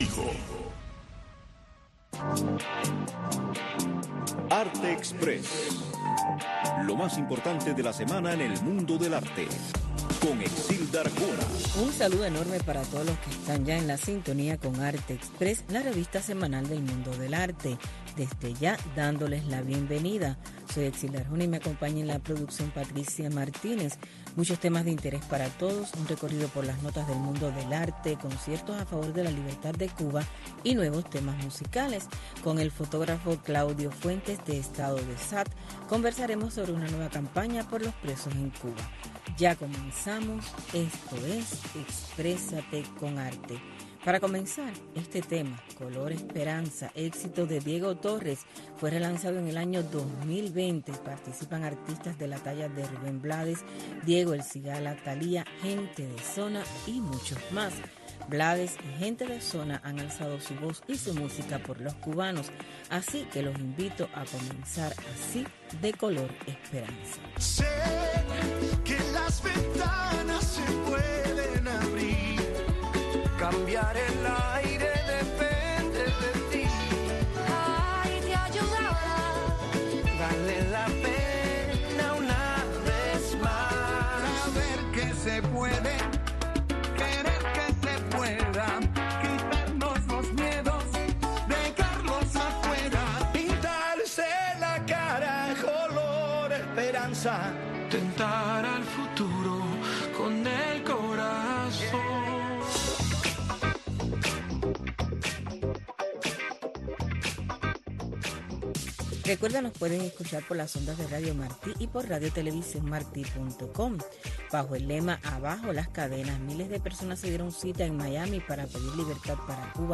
Arte Express, una revista informativa - cultural con noticias, eventos, blogs cubanos, segmentos varios, efemérides, música y un resumen de lo más importante de la semana en el mundo del arte.